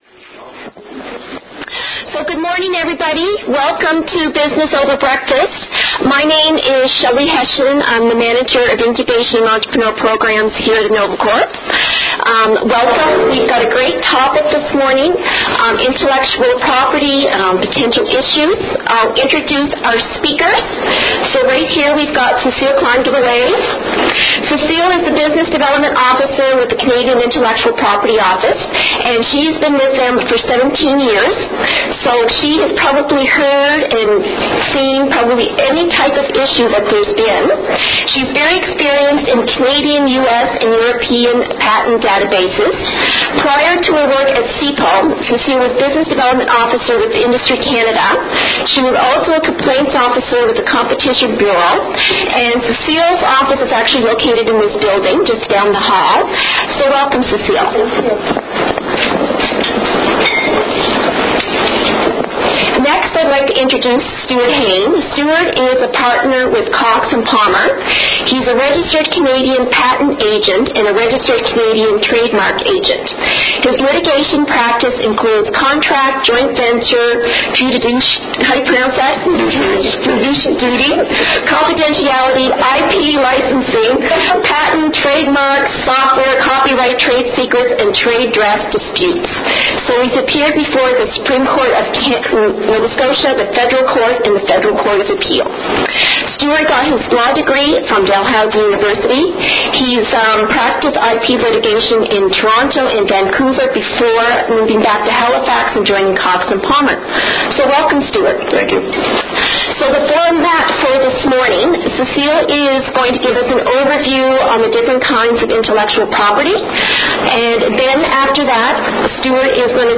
Click here to listen to a podcast of the event below that took place on February 28, 2013, at the Innovacorp Enterprise Centre.